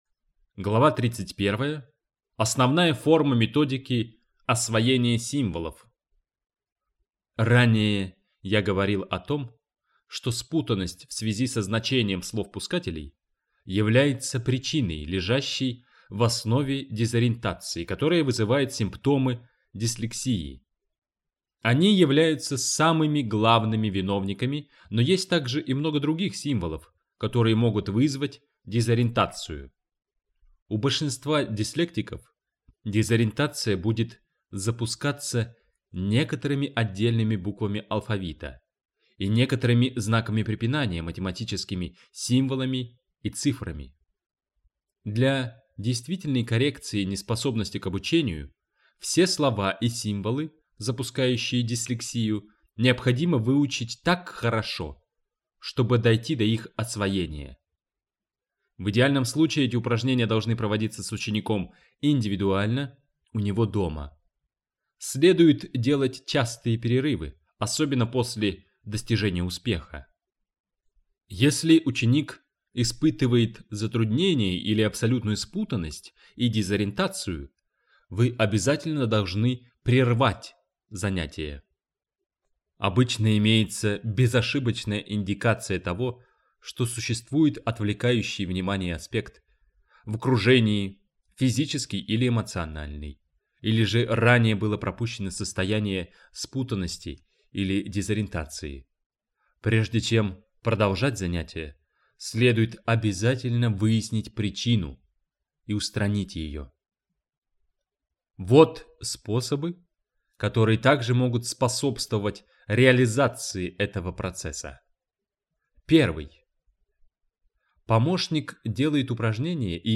Дар Дислексии - Аудиокниги - Каталог файлов - Дислексия и методика Дейвиса